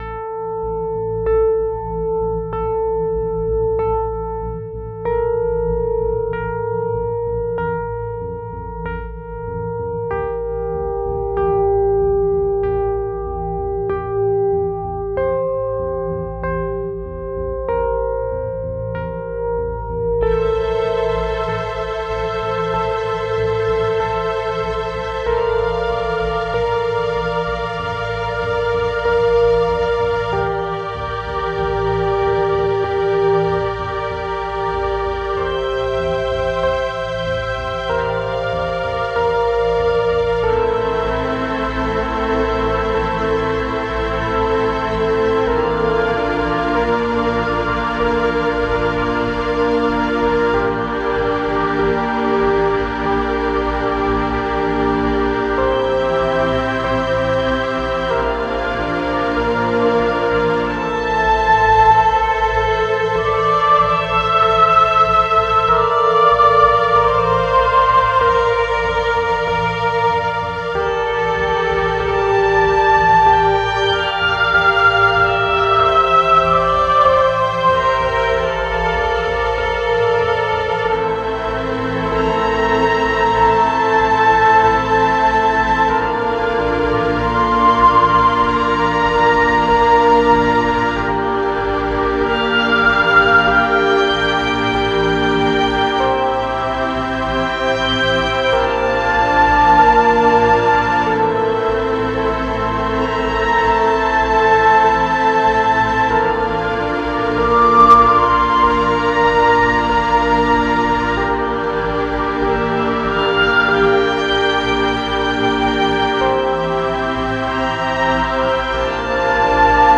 It's much more traditionally orchestral than I am used to but uses mostly the same instruments as 060. Except there's chelli now and I use a prophet synth (well, a vst meant to sound like a prophet synth called prophanity) and no FM synth.